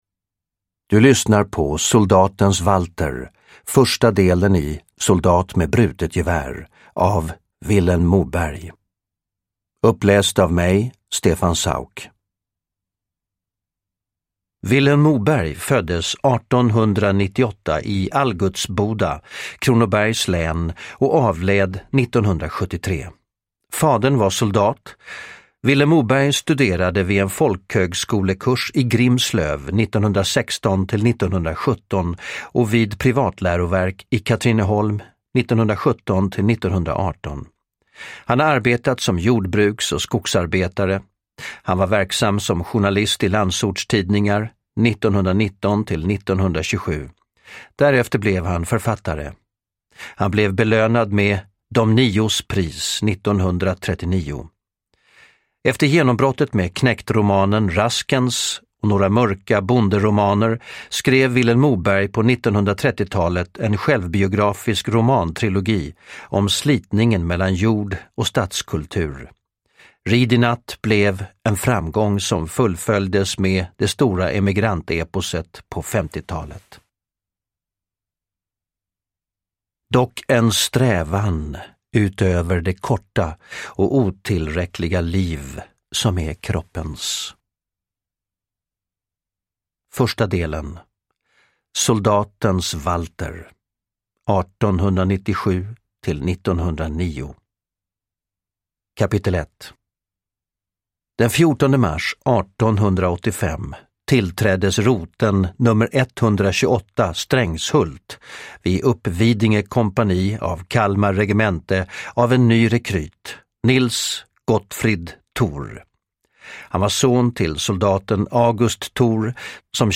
Uppläsare: Stefan Sauk